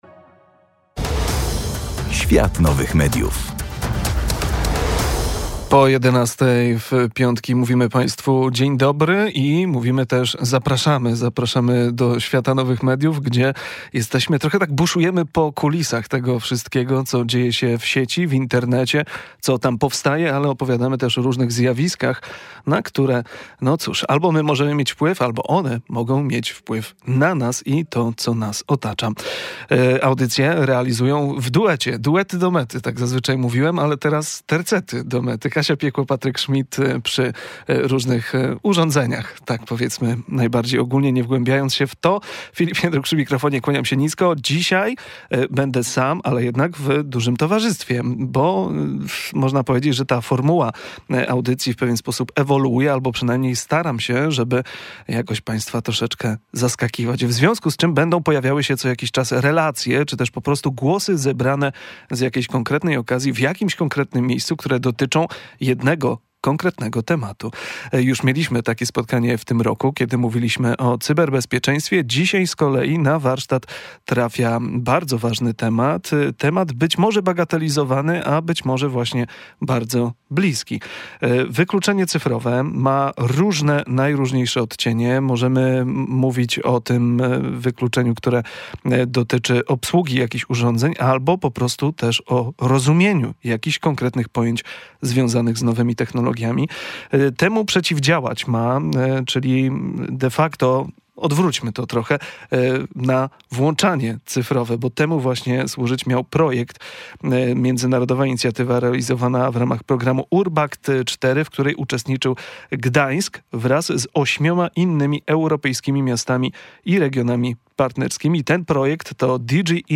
W ramach projektu wypracowano sześć konkretnych rozwiązań, które będą wdrażane w Gdańsku, w tym rozwój centrów wsparcia cyfrowego w domach i klubach sąsiedzkich. Zachęcamy do wysłuchania relacji z gali zakończenia projektu w gdańskim Starterze.